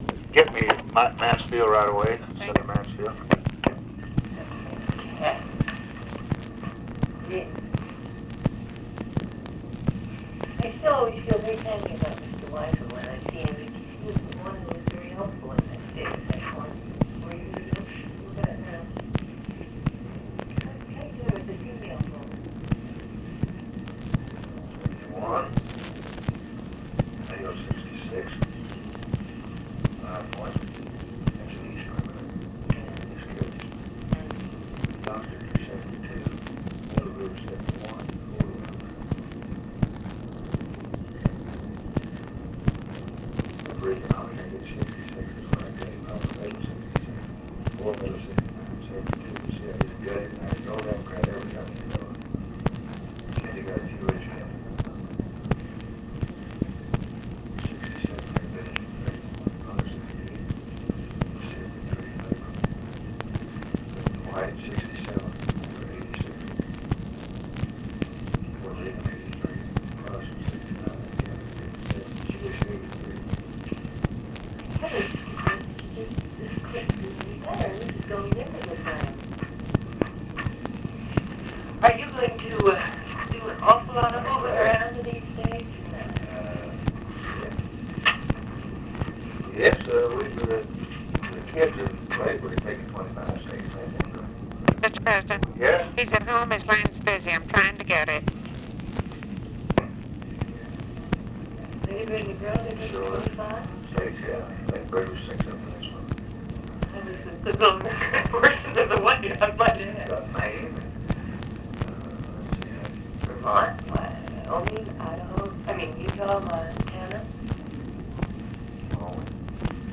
Conversation with TELEPHONE OPERATOR and OFFICE CONVERSATION
Secret White House Tapes